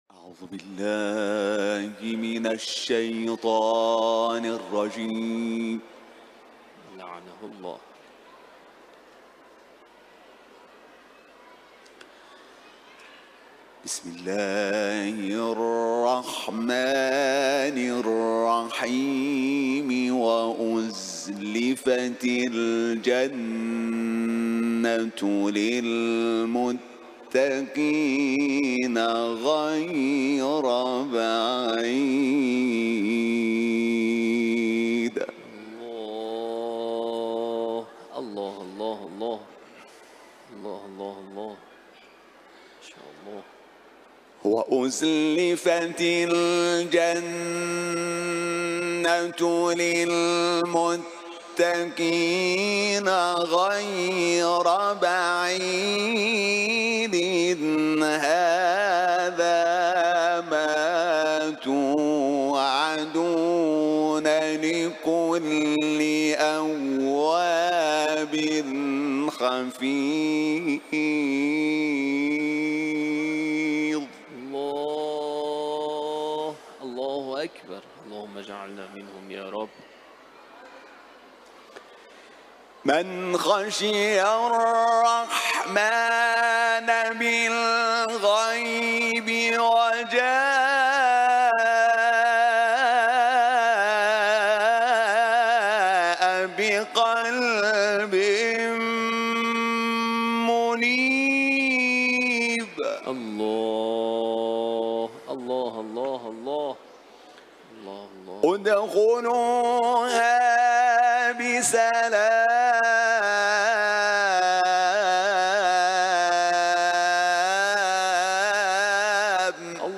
Kur’an tilaveti
İranlı Uluslararası Kur’an kârisi